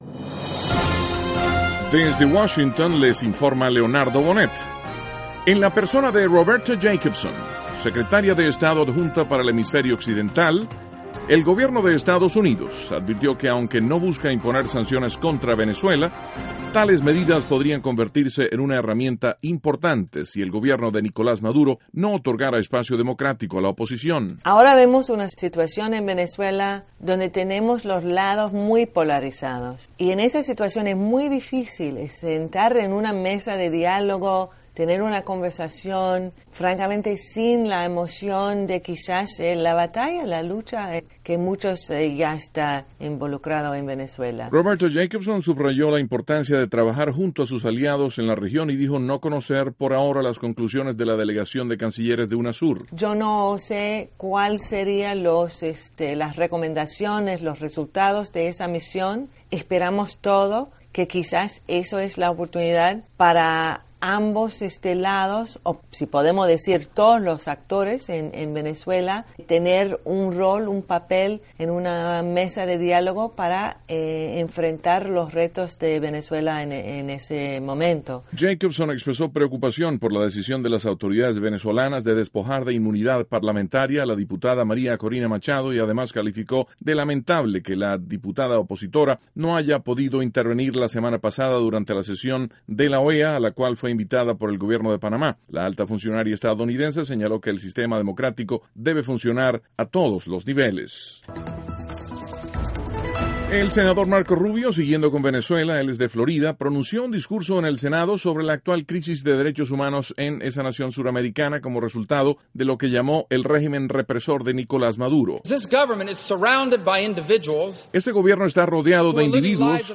(2 Sonidos Jacobson) 2.- El Senador Marco Rubio, de Florida, propuso en un discurso ante el Senado, imponer sanciones contra individuos relacionados con el gobierno de Nicolás Maduro. (Sonido Rubio) 3.- El presidente Obama visita al Papa Francisco, en Roma.